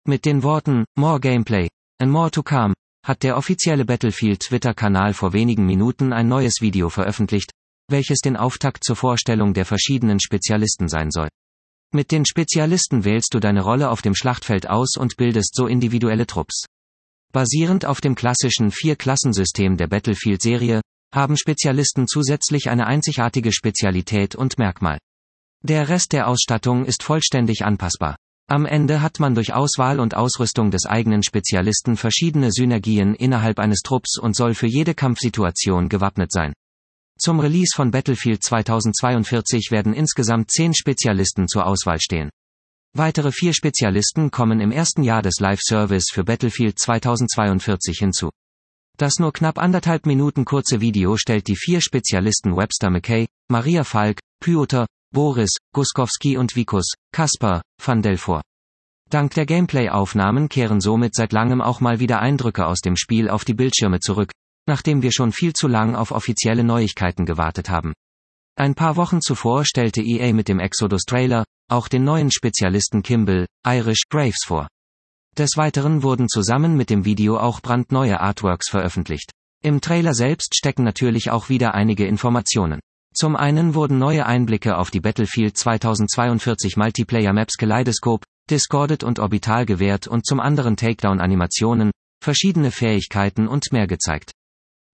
Als Killbestätigung okay, aber dieses “flapflap” Gedöns nervt jetzt schon.